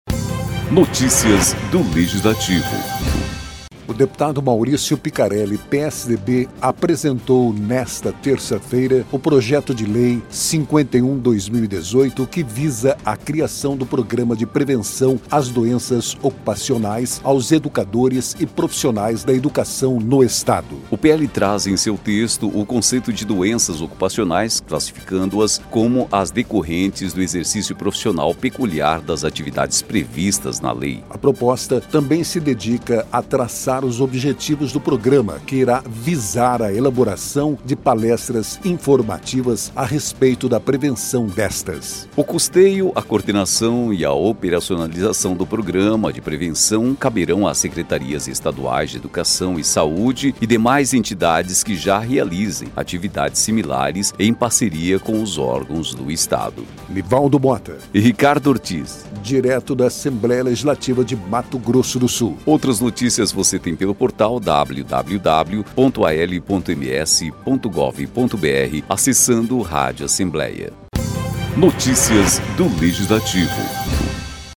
“É importante a existência da semana específica para atenção a esse assunto, mas devido ao elevado índice  de profissionais acometidos por doenças ocupacionais, fez-se necessária a instituição de um programa permanente de prevenção”, explicou Picarelli.